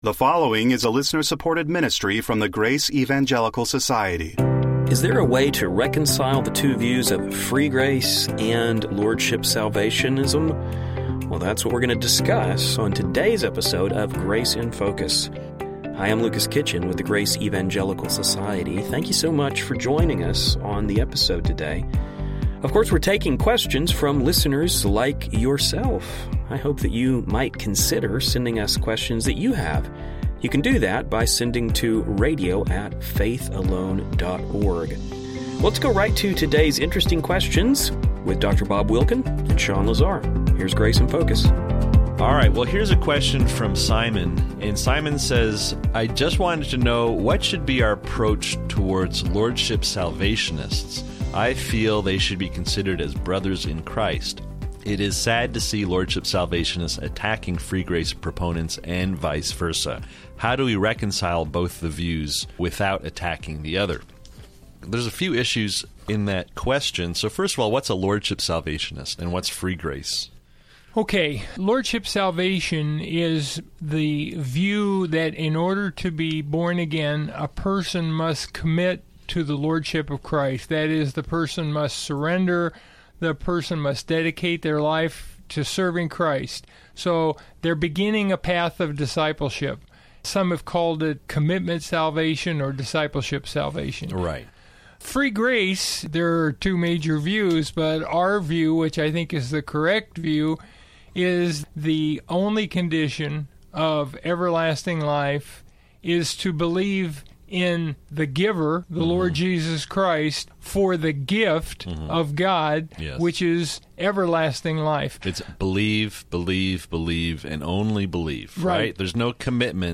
First, we will hear the guys unpack the differences between these two views.
In addition, we will hear the guys address how these two camps should interact with one another.